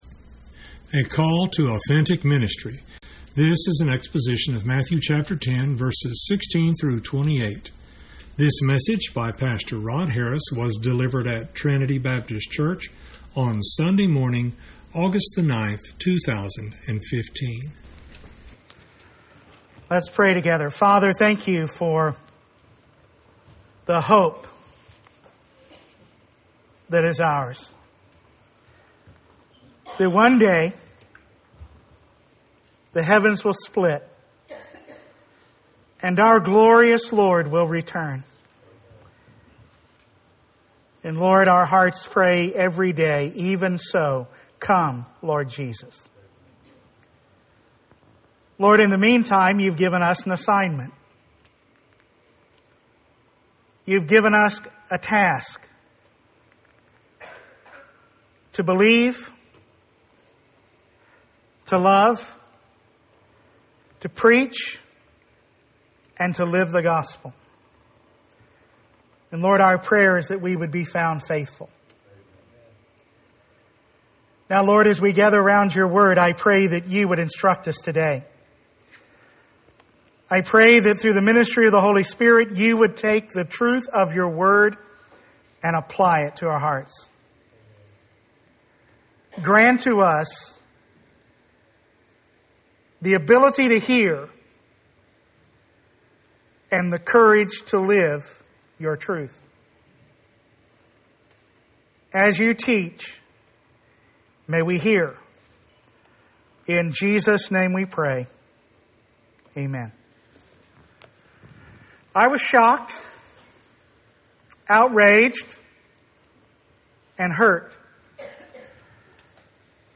Sunday morning preaching